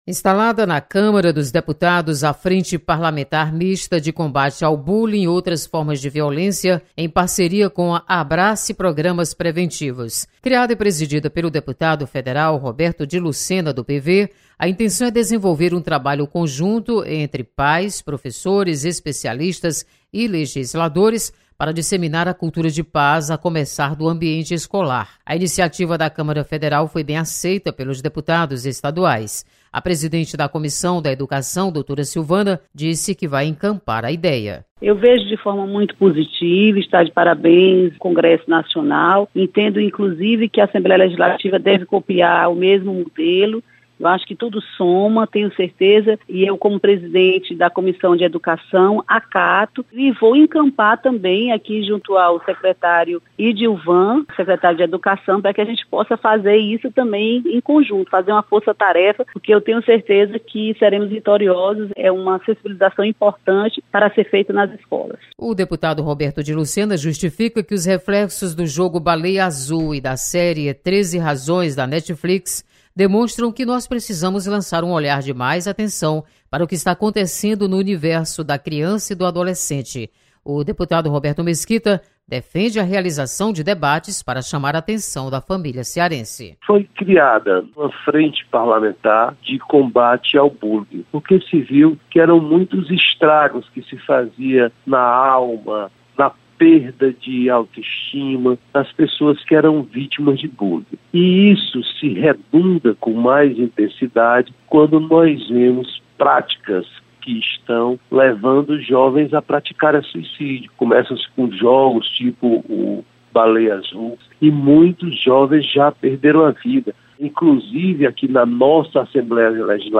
Deputados comentam sobre instalação de Frente Parlamentar de Combate ao Bullying.